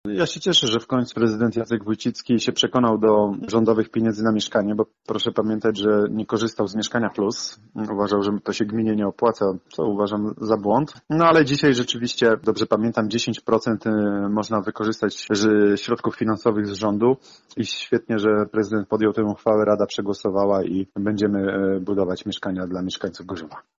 W planach jest budowa 93 mieszkań i 3 punktów handlowo-usługowych. Planowany koszt realizacji inwestycji to prawie 32 miliony złotych. 10 procent tej kwoty miasto chce pozyskać ze środków Rządowego Funduszu Rozwoju Mieszkalnictwa. Mówi wiceprzewodniczący Rady Miasta, Sebastian Pieńkowski: